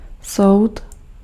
Ääntäminen
France: IPA: [kuʁ]